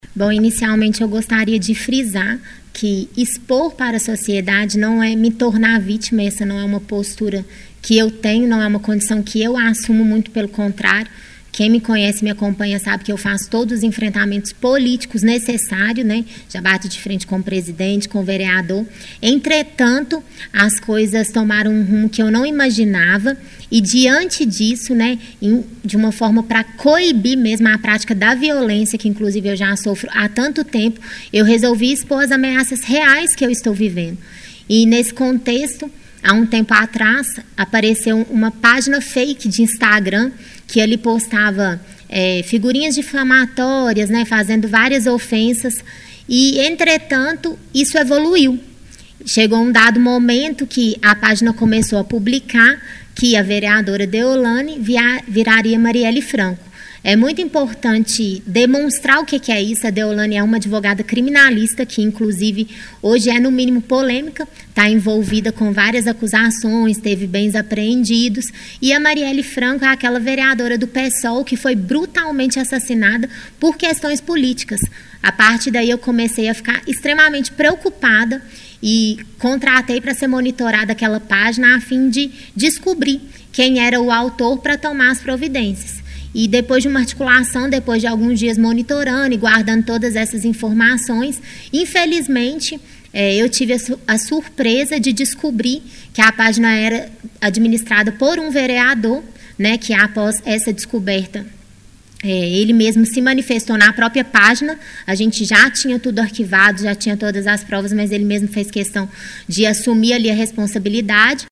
Ela convidou a imprensa para uma coletiva onde falou sobre o que vem sofrendo nos últimos meses.
Durante a entrevista coletiva a vereadora explicou que decidiu expor as ameaças por temer pela própria vida: